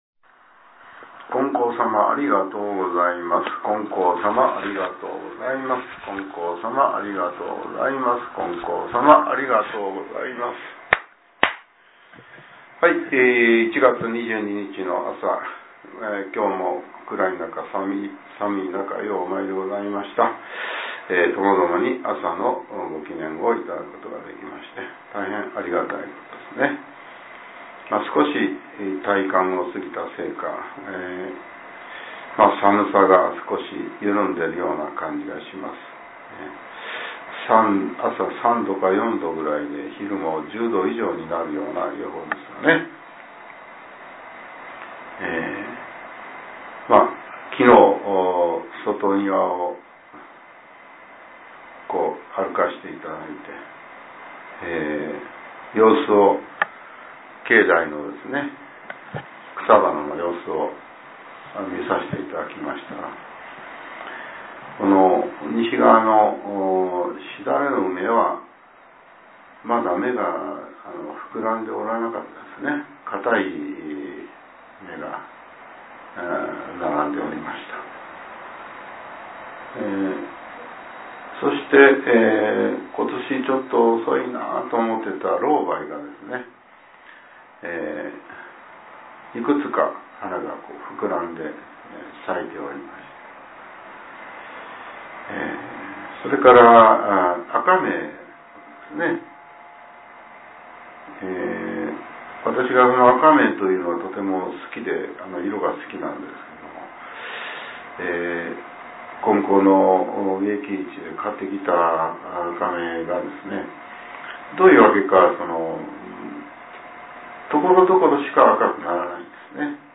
令和７年１月２２日（朝）のお話が、音声ブログとして更新されています。